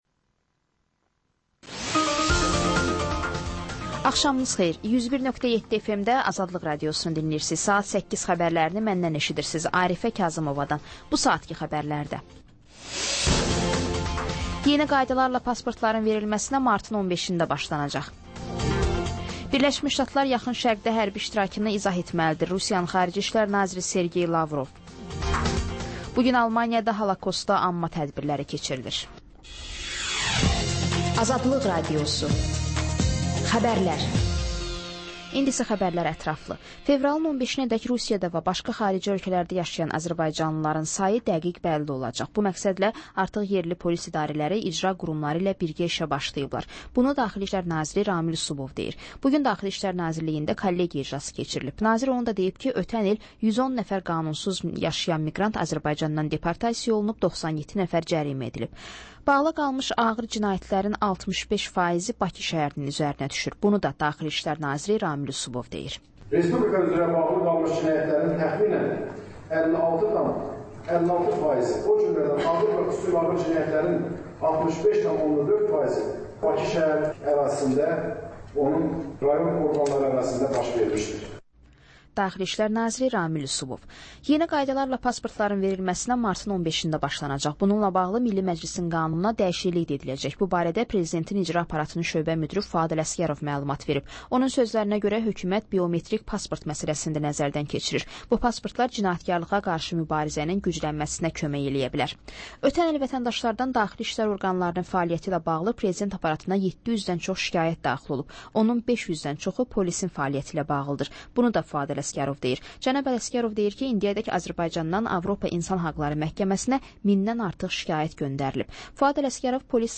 Xəbərlər, reportajlar, müsahibələr. Panorama: Jurnalistlərlə həftənin xəbər adamı hadisələri müzakirə edir.